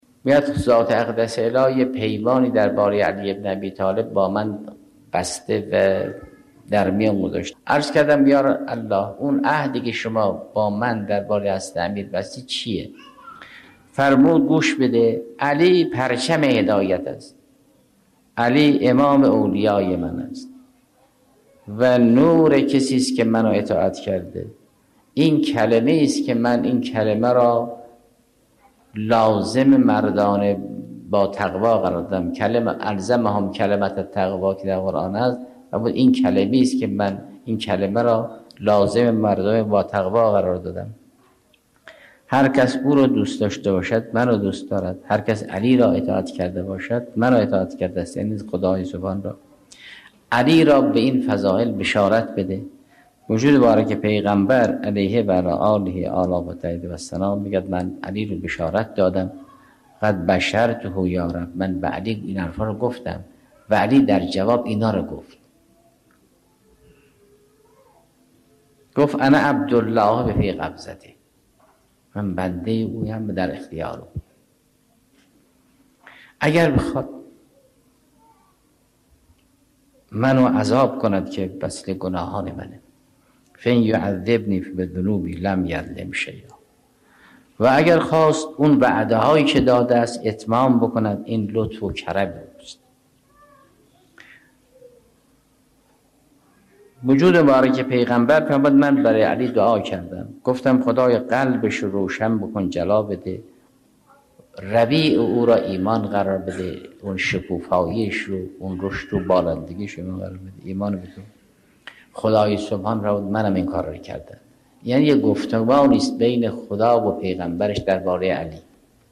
آیت‌الله جوادی آملی در یکی از سخنرانی‌هایشان فرمودتد: ذات اقدس پروردگار، عهد و پیمانی را درباره امیرالمؤمنین(ع) با نبی مکرم اسلام(ص) در میان گذاشته است؛ آن عهد این است که علی(ع) پرچم هدایت، امام اولیای من و نور افرادی است که مرا اطاعت کرده‌اند؛ و کلمه‌ایست که من آن کلمه را لازمه افراد با تقوا قرار دادم.